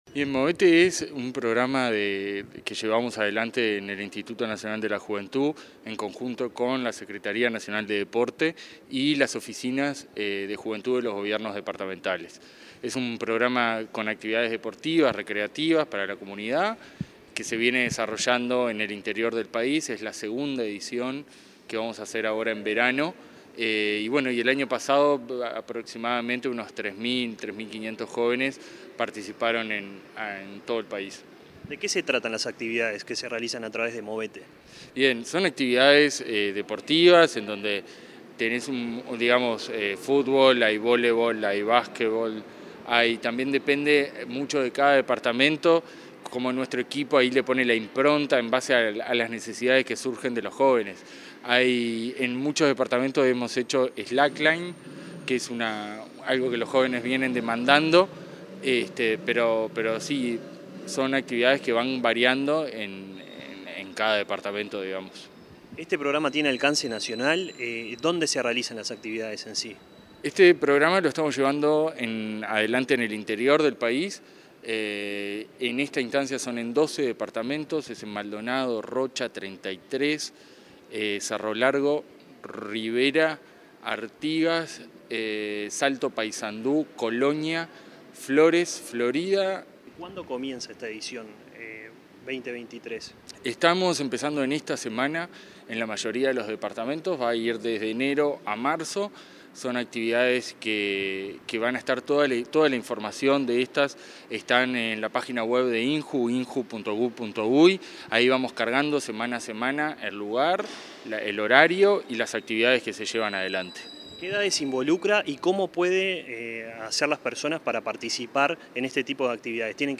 Entrevista al director de la División Descentralización y Territorio del INJU, Federico Delgado